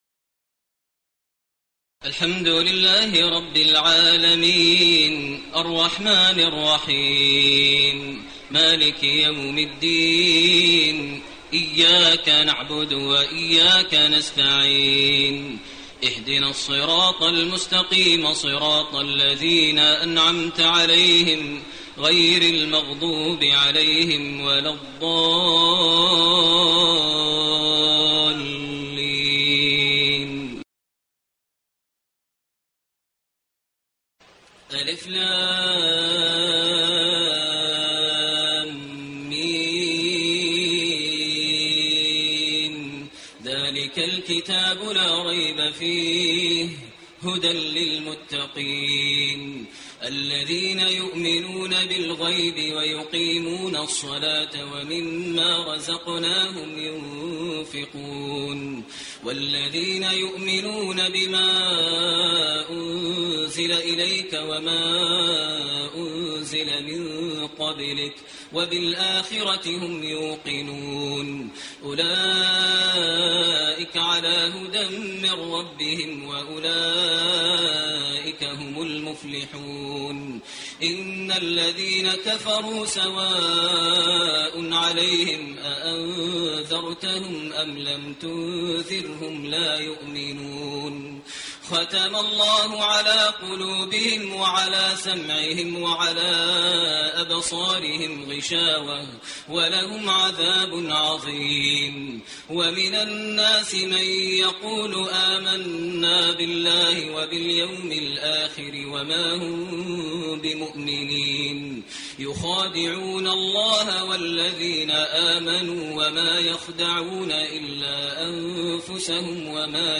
تراويح الليلة الأولى رمضان 1429هـ من سورة البقرة (1-74) Taraweeh 1st night Ramadan 1429 H from Surah Al-Baqara > تراويح الحرم المكي عام 1429 🕋 > التراويح - تلاوات الحرمين